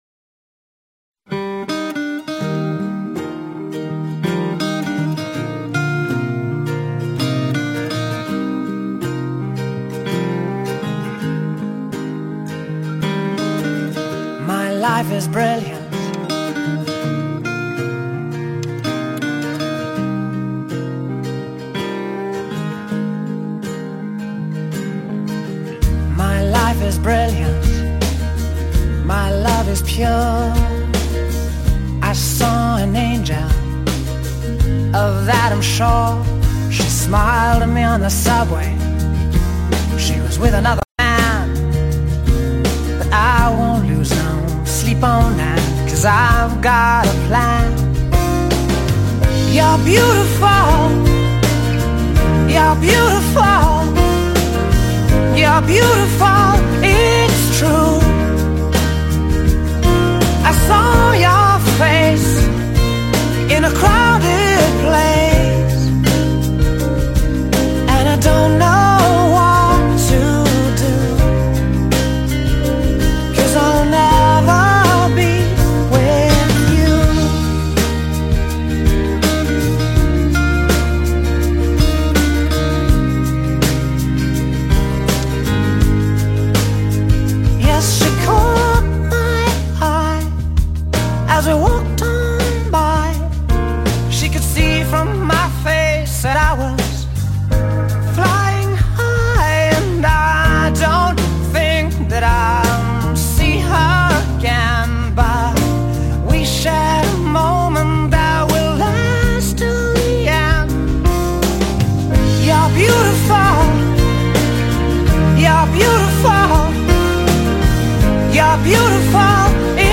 pop ballads